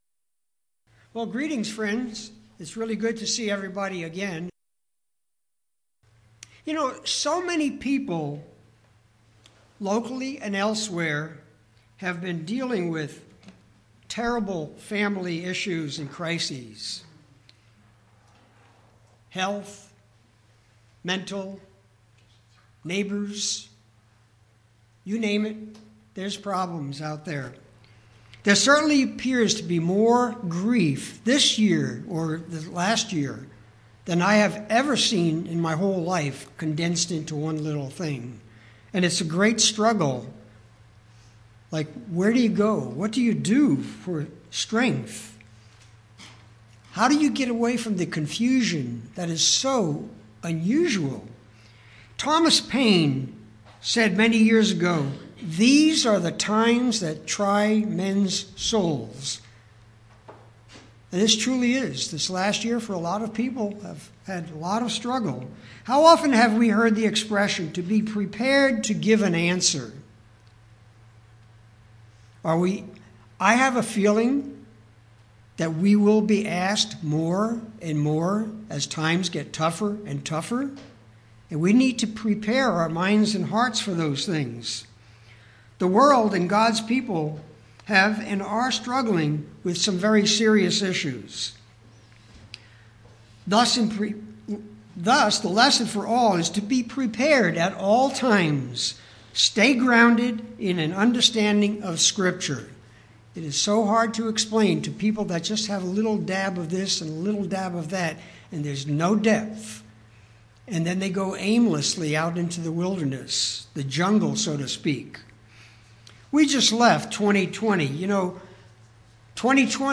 Sermons
Given in Yuma, AZ